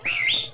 R2D2-hey-you.mp3